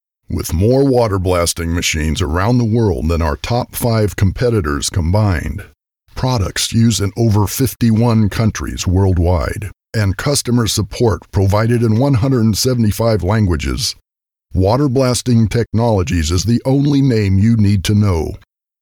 Male
a deep baritone voice with some grit and a tone of wisdom, authority, warmth and trust
Sample Of Studio Quality Audio